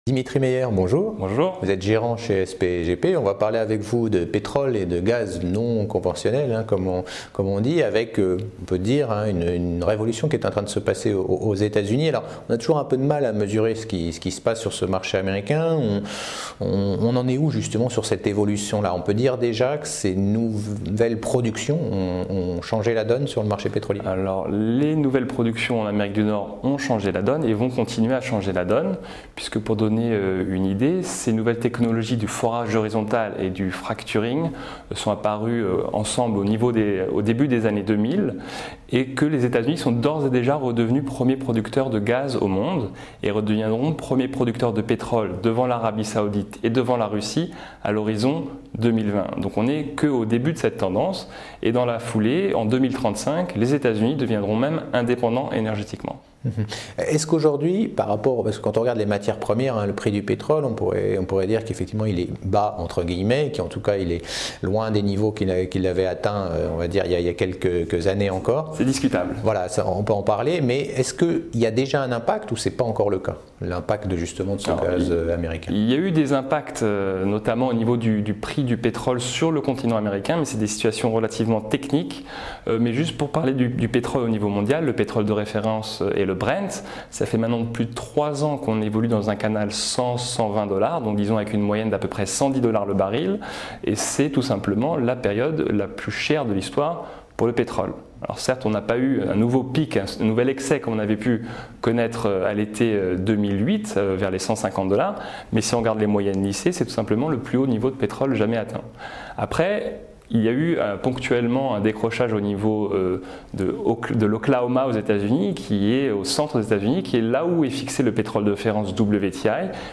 Tv Bourse : Interview